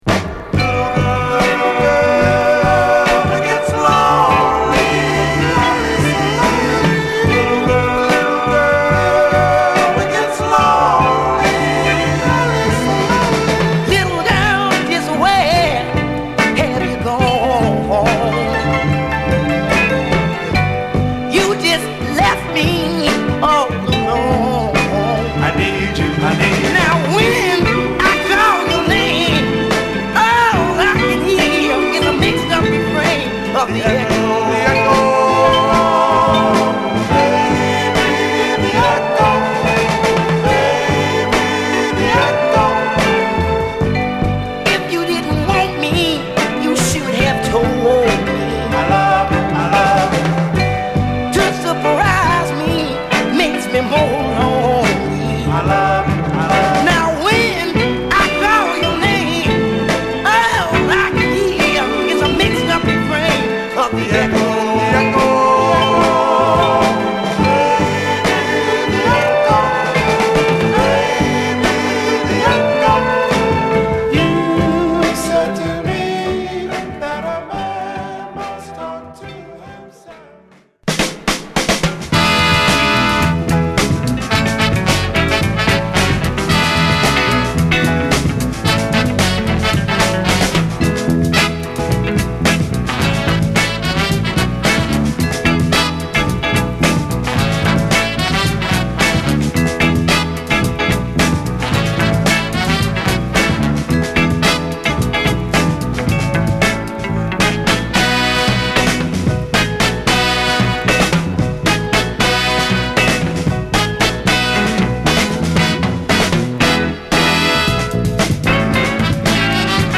フィラデルフィア録音
緩やかな演奏に抜群のコーラスワークもハマった極上スウィート！！